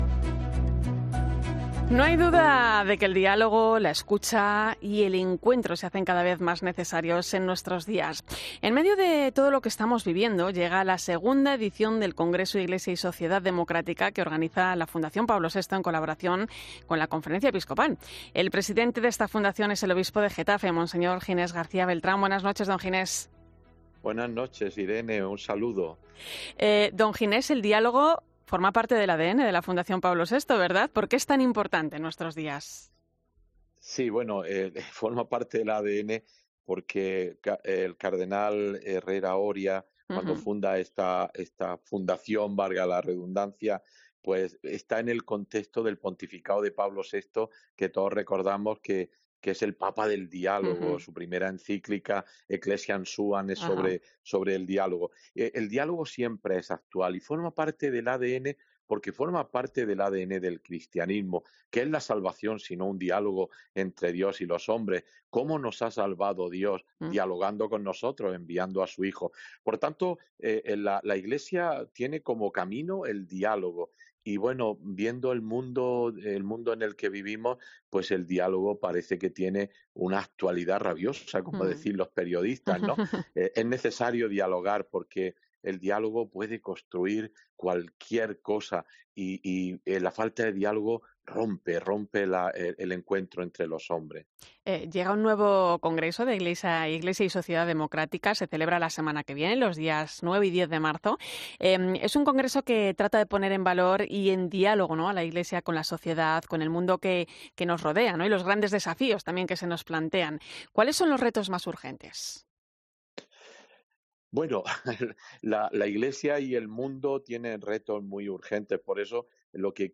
El obispo de Getafe y presidente de la Fundación Pablo VI ha explicado en 'La Linterna de la Iglesia' los pormenores del II Congreso 'Iglesia y Sociedad Democrática'